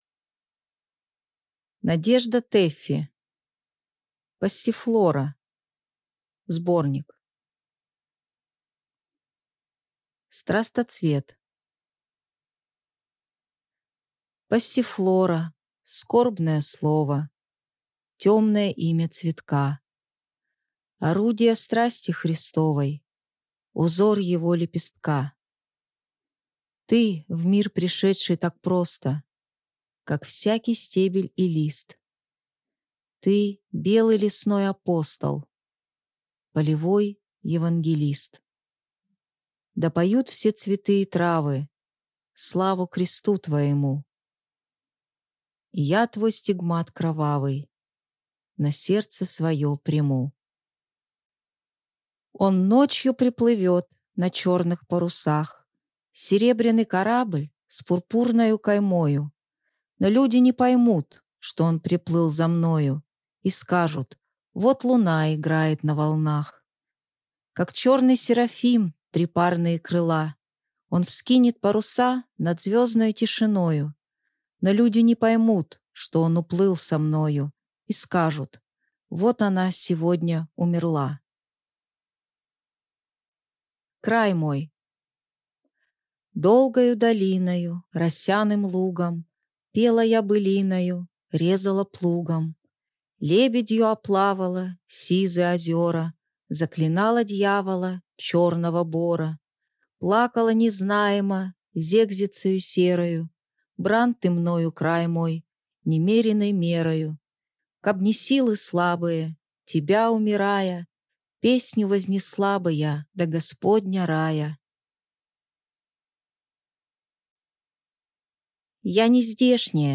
Аудиокнига Passiflora (сборник) | Библиотека аудиокниг